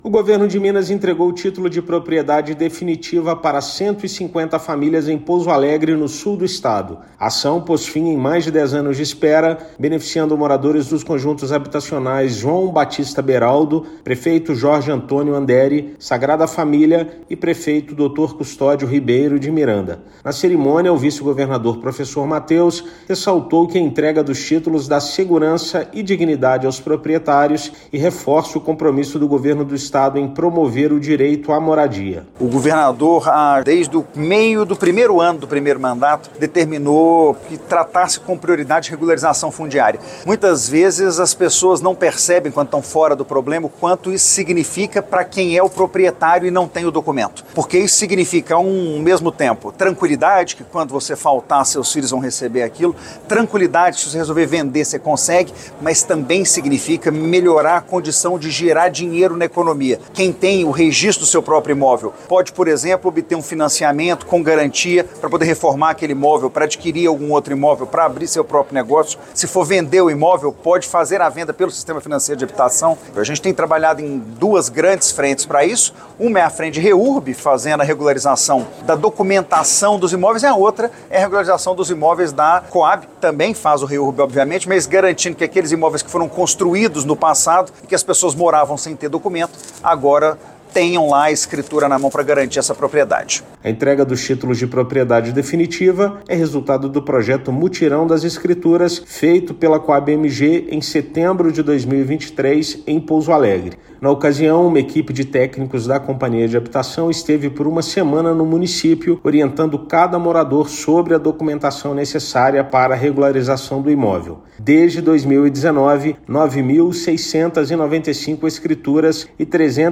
Ação coloca fim em mais de dez anos de espera de moradores de diversos conjuntos habitacionais. Ouça matéria de rádio.